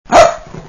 Pianeta Gratis - Audio - Animali
cani_dog08.wav